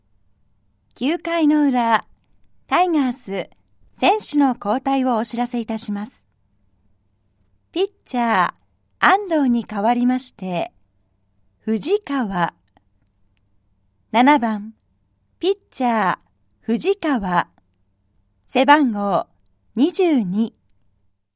ボイスサンプル
アナウンス